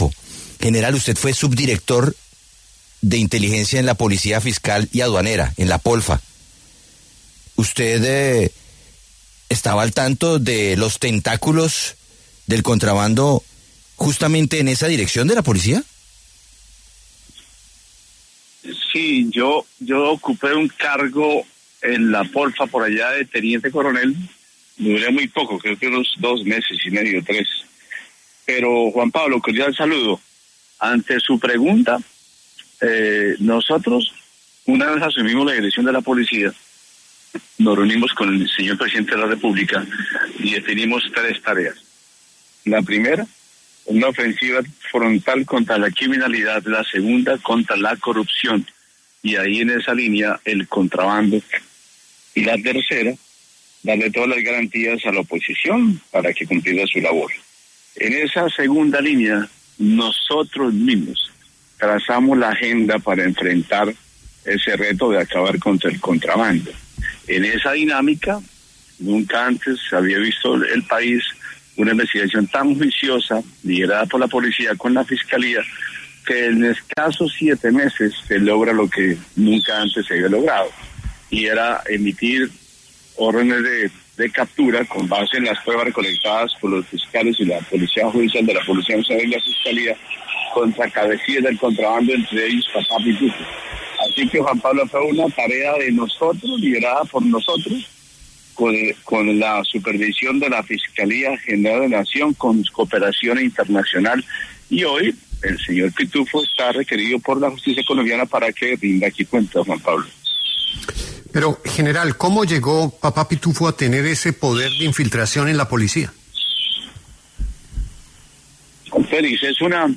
El general (r) William Salamanca, exdirector de la Policía Nacional, conversó con La W sobre la lucha contra el contrabando.